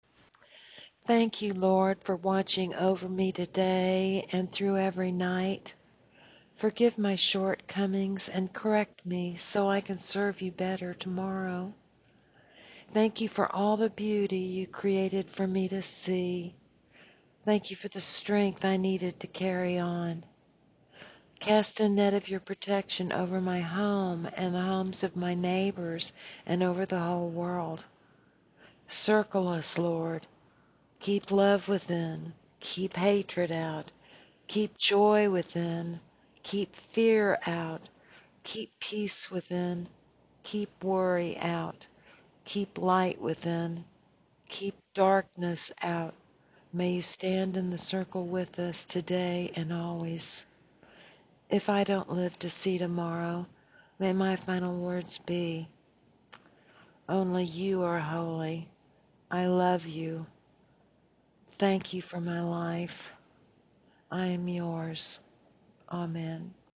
Evening Prayer
chicchan-evening-prayer.m4a